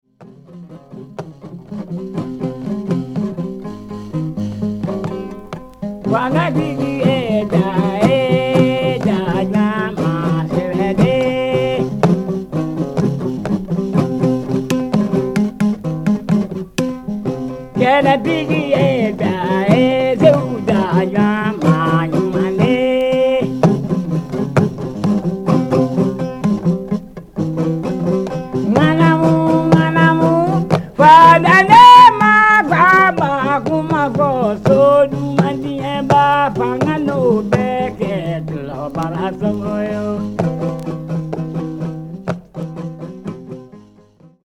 USED LP Mono